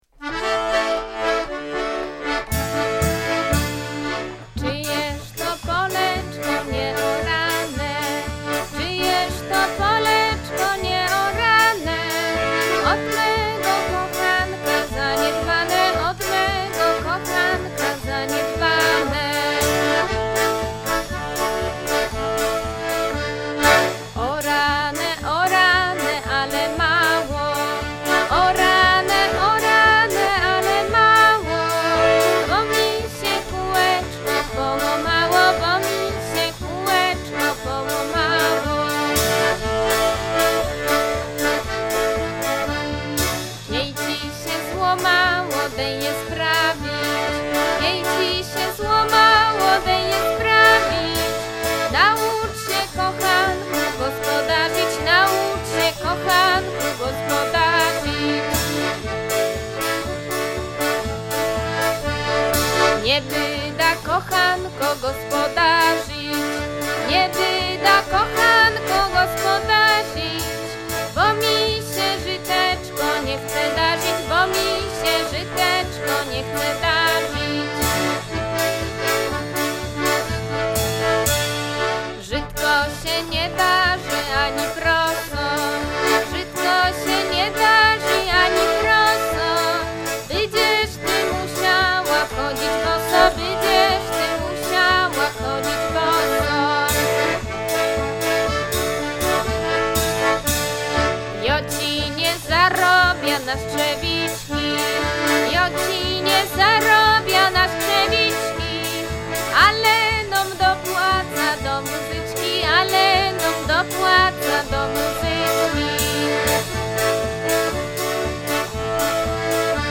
Wraz z Kapelą Fedaków zapraszamy do międzypokoleniowego śpiewania górnośląskich przyśpiewek. W tej części prezentujemy utwory pochodzące z Piotrowic.